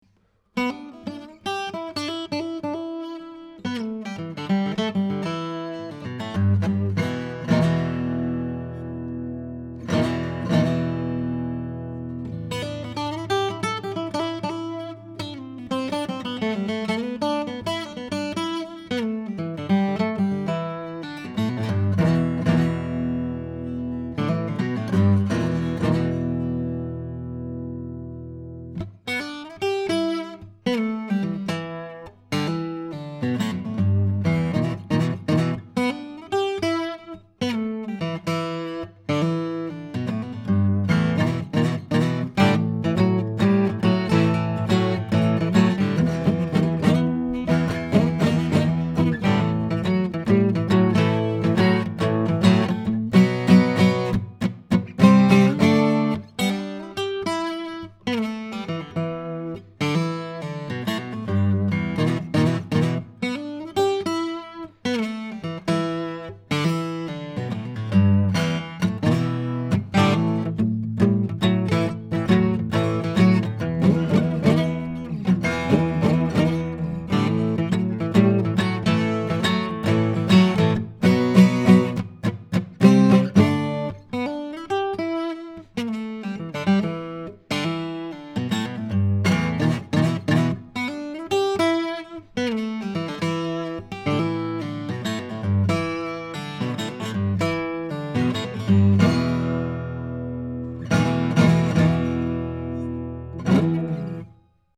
1993 GUILD JF55-12 NTE 12-String Guitar
Here are 19 quick, 1-take MP3 sound files of myself playing this guitar, to give you an idea of what to expect. The guitar is a powerful Jumbo 12-string, and delivers the highly-coveted Guild 12-string acoustic sound with a nice balance between the upper and lower registers, and of course, the amazing 12-string "ring" effect for which Guilds are legendary. These files are using a vintage Neumann U87 mic into a Sony PCM D1, flash recorder, with MP3s made in Logic, with no EQ, compression, or any other special effects.
It has a powerful voice, but when played softly it also has a very fine shimmering quality.